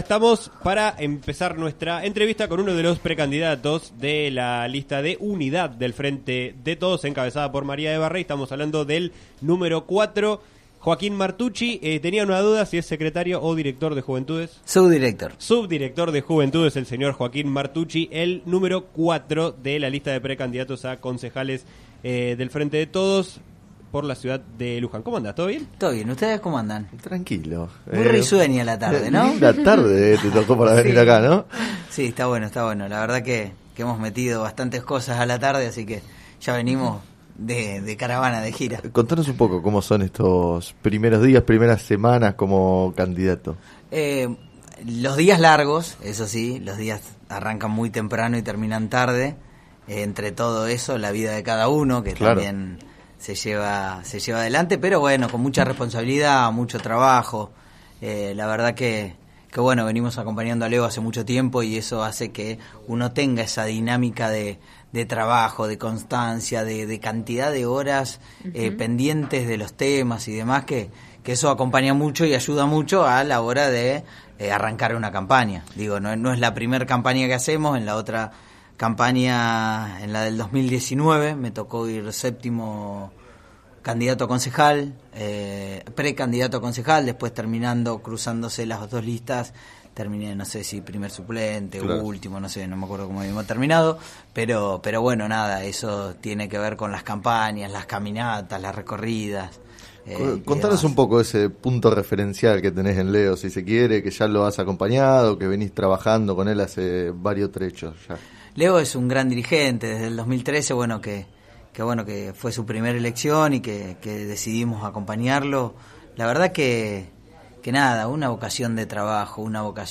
entrevistado en el programa “Sobre las cartas la mesa” de FM Líder 97.7